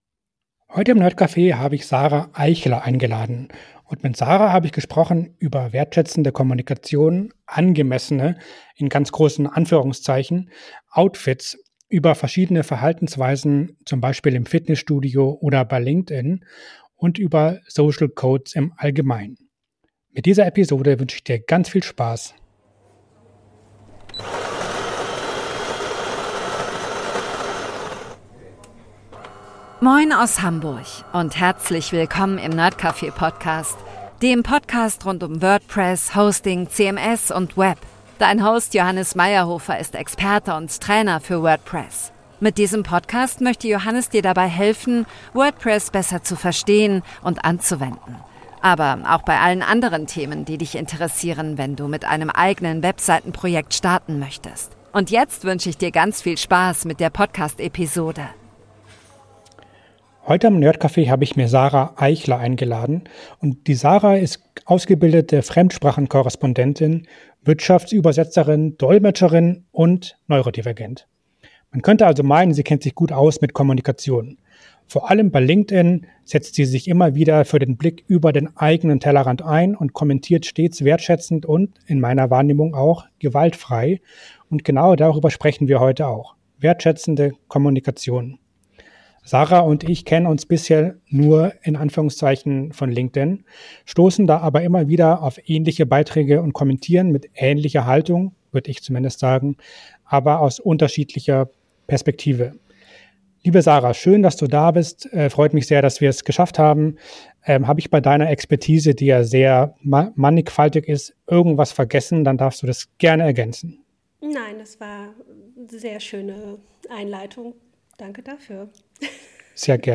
Der nerdcafe Podcast steht für ein offenes, vielfältiges und zugängliches Internet – verständlich erklärt, entspannt im Ton, aber mit Tiefgang.